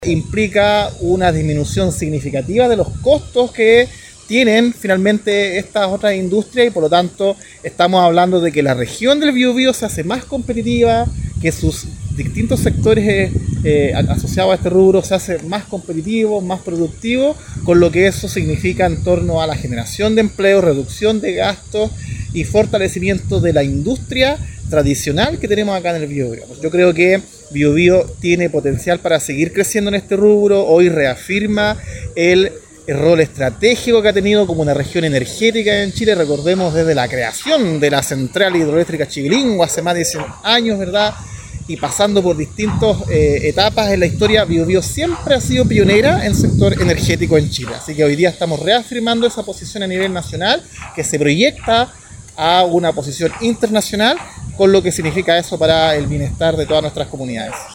Javier Sepúlveda, seremi de Economía, informó que “se están transfiriendo cerca de 16 mil metros cúbicos diarios de petróleo, cosa que hace dos años no existía y que ha permitido reactivar todo un encadenamiento productivo asociado al sector petroquímico, a industrias químicas”.